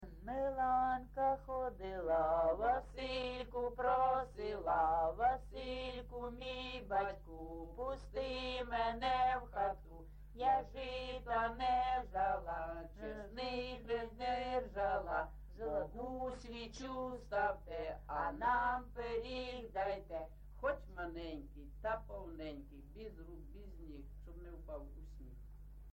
ЖанрЩедрівки
Місце записус. Званівка, Бахмутський район, Донецька обл., Україна, Слобожанщина